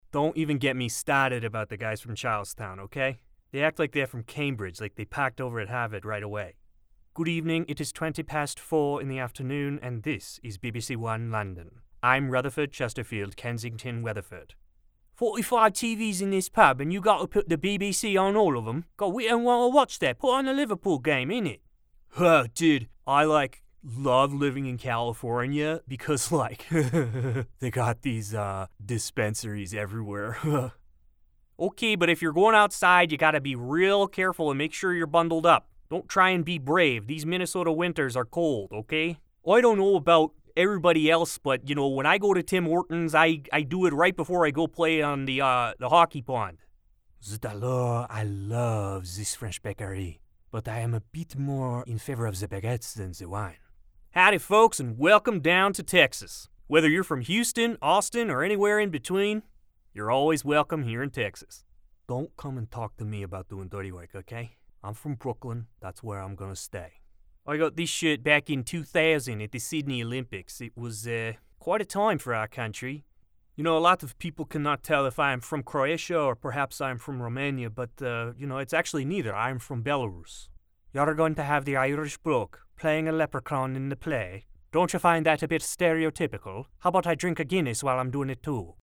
Demo
Adult, Young Adult
Has Own Studio
Everyman, natural voice with range.
standard us